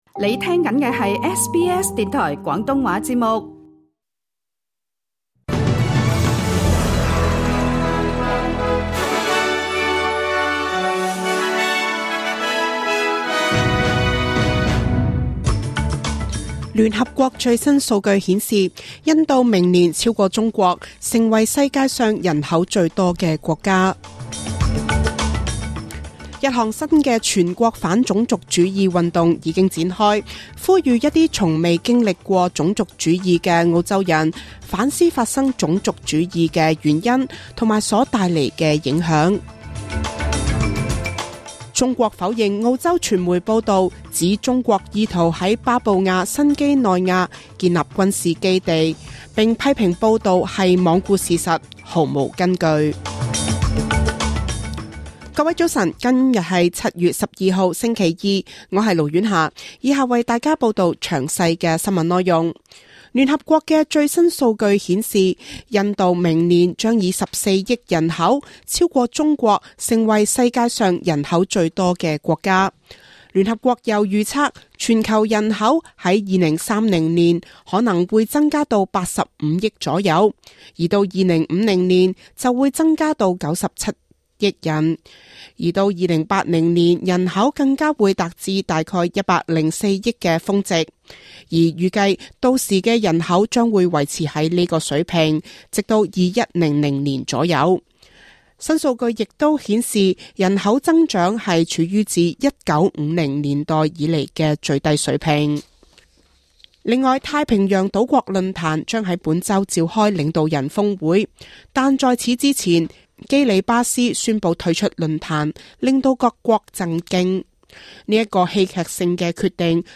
SBS 中文新聞（7月12日）